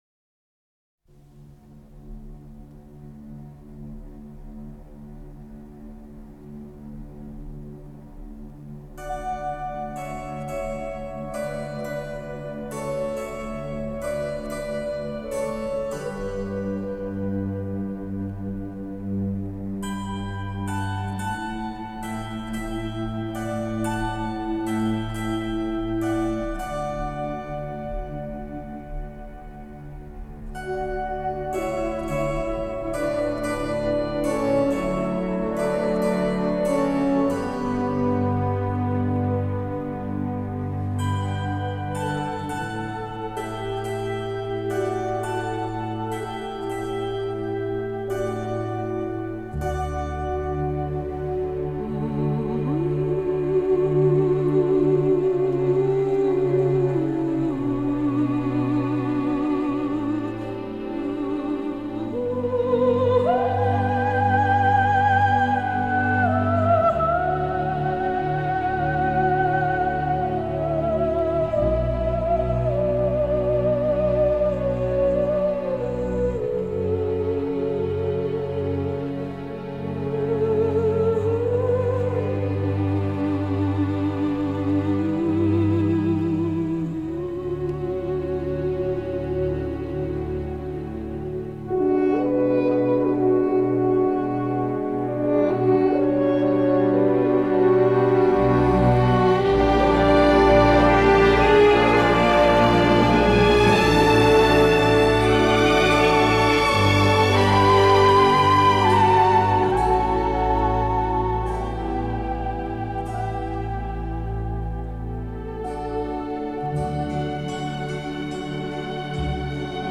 上传的这个试听曲是其电影原声，因为有多个主题音乐版本，不知哪个版本是你所要的那个版本。